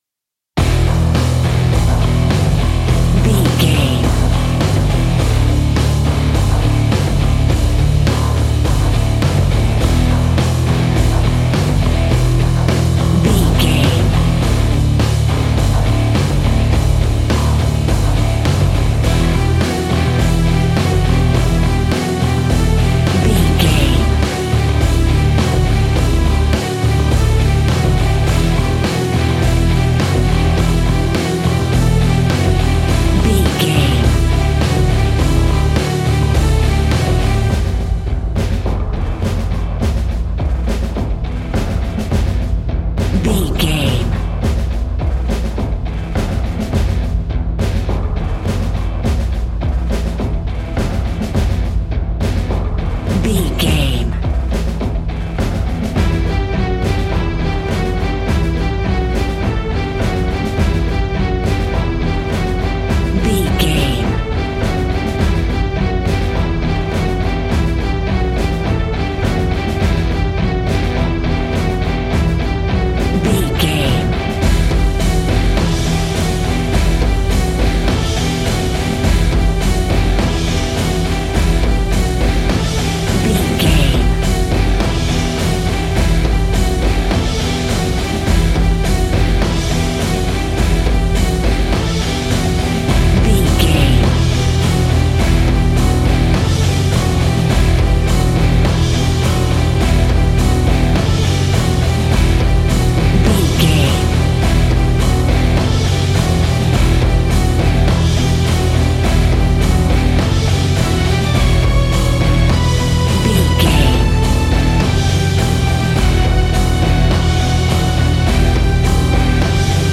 Epic / Action
Fast paced
Aeolian/Minor
hard rock
guitars
instrumentals
Heavy Metal Guitars
Metal Drums
Heavy Bass Guitars